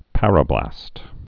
(părə-blăst)